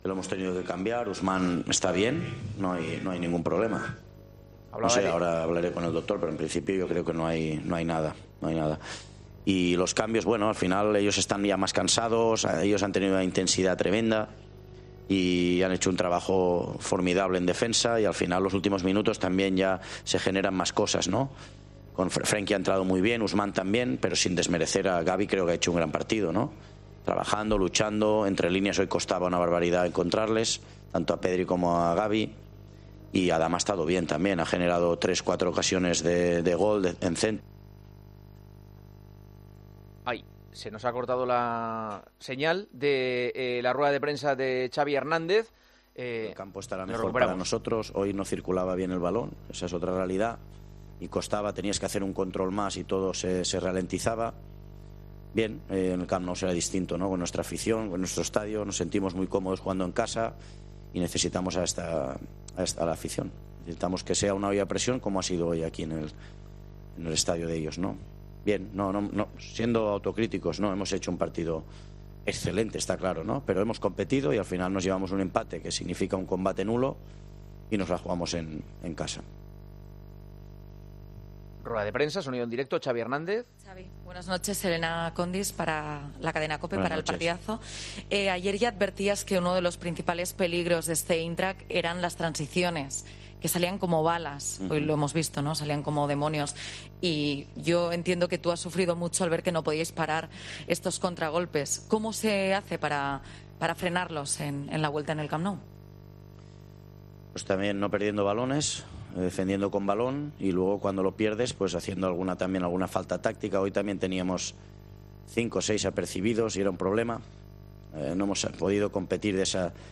Xavi, en rueda de prensa: "Viendo el partido que hemos hecho, es un buen resultado para nosotros"